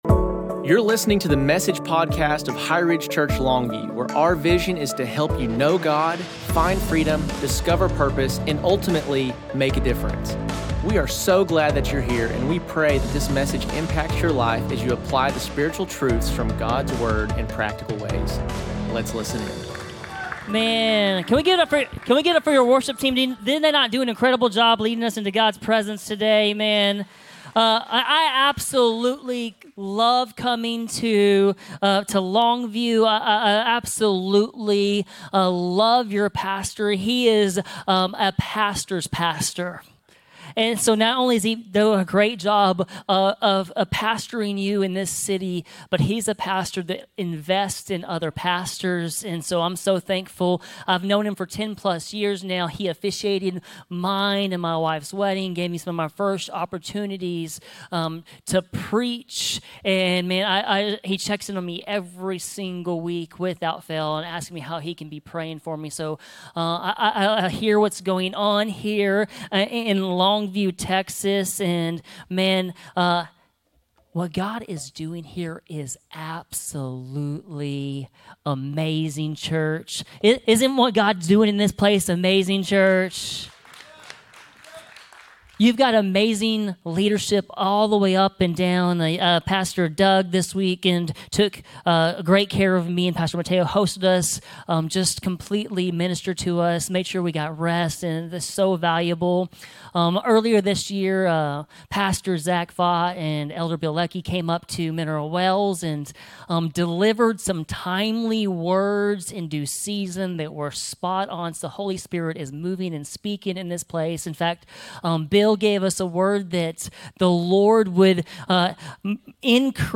2025 Message